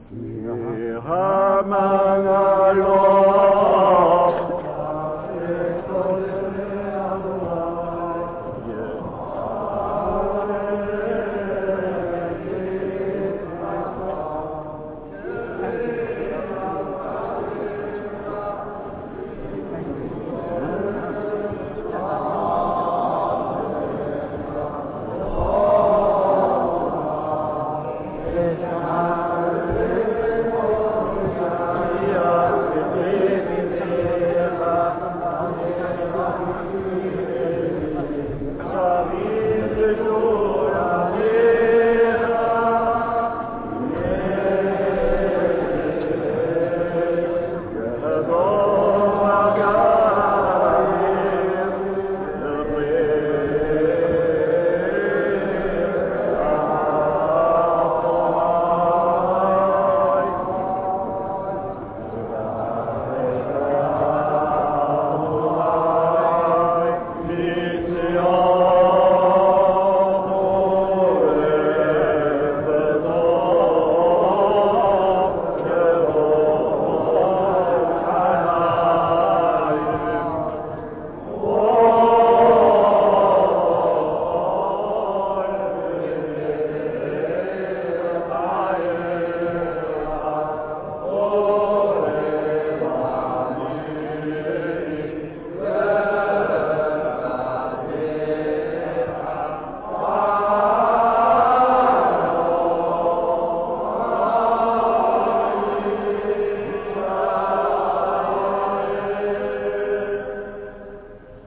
This structure is used during a private Limud (Lezing) and on 15 Shevat.
Some parts are sung when used
Psalm 29, psalm 67 various melodies for psalms 117-118 and psalm